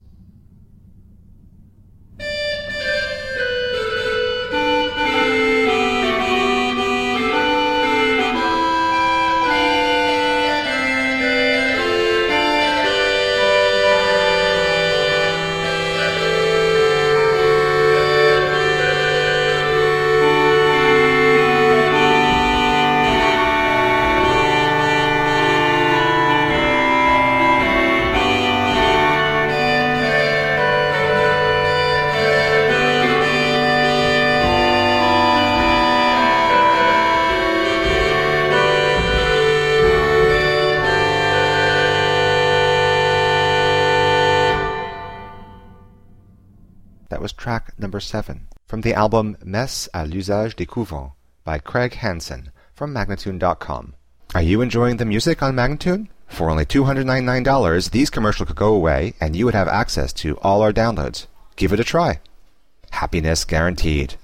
Exquisitely moving baroque organ music.
Classical, Baroque, Instrumental
Organ